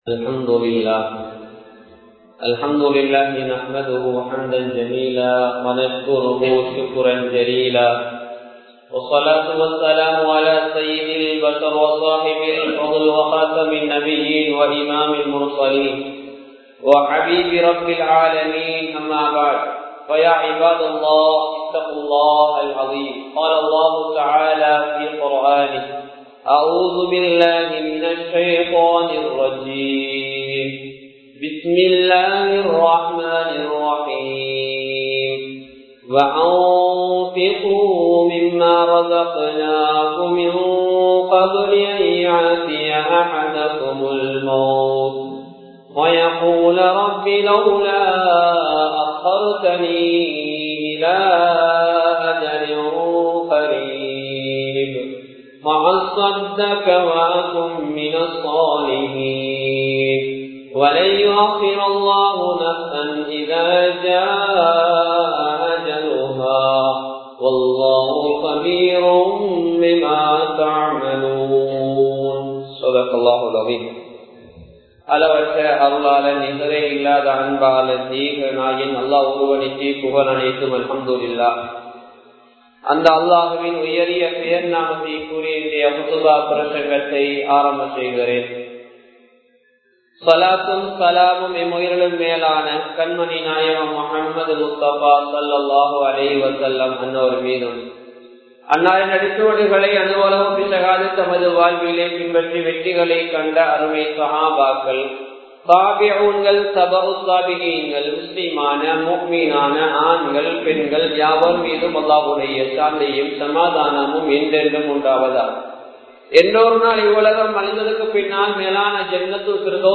ரமழானும் ஸதகாவும் | Audio Bayans | All Ceylon Muslim Youth Community | Addalaichenai
Majmaul Kareem Jumua Masjidh